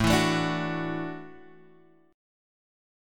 A6sus4 chord {5 7 7 7 7 x} chord